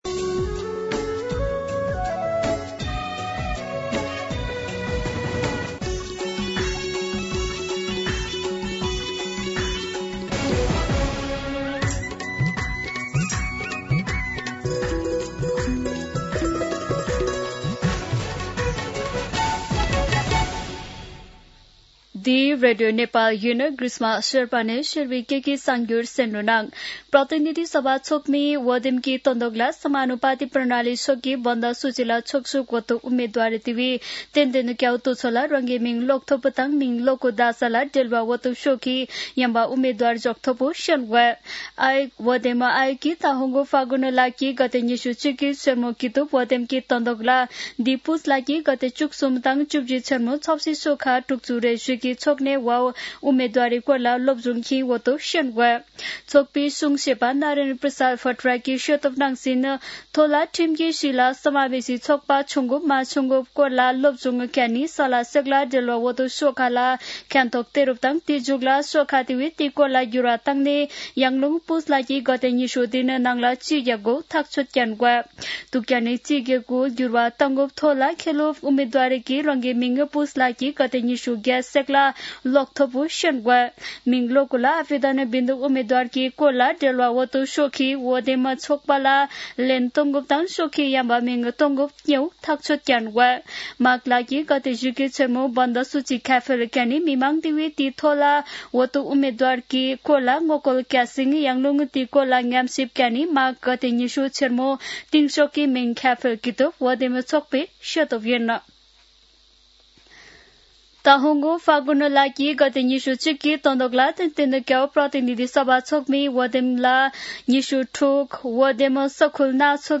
शेर्पा भाषाको समाचार : १९ पुष , २०८२